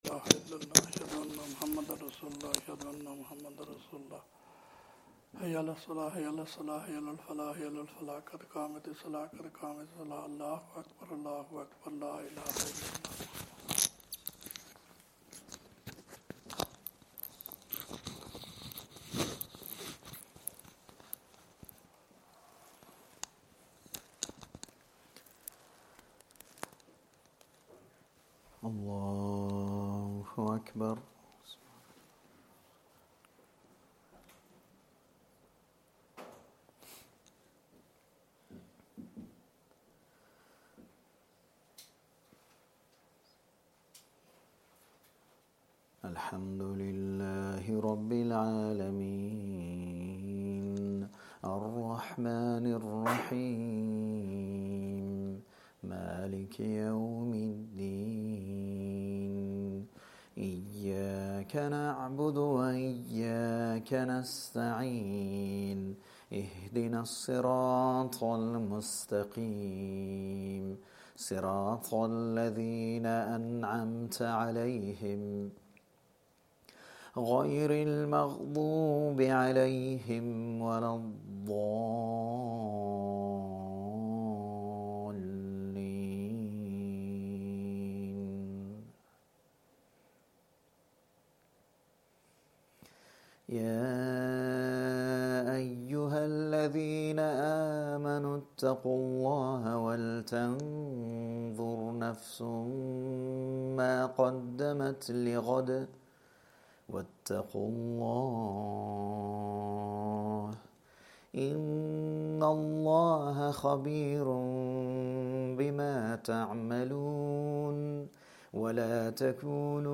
Madni Masjid, Langside Road, Glasgow